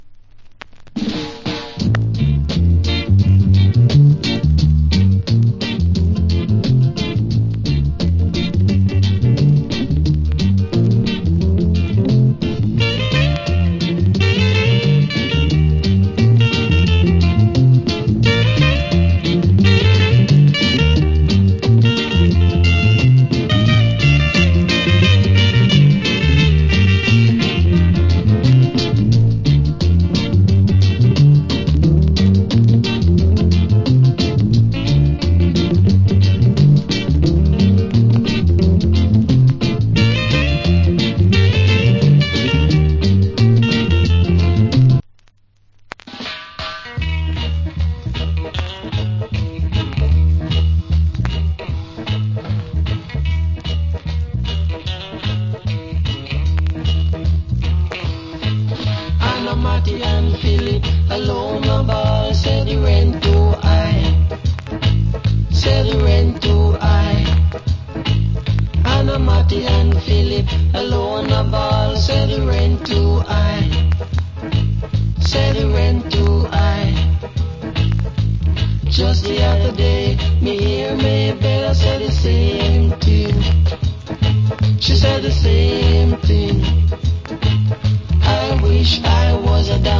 Wicked Inst.